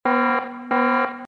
baojing_alarm.mp3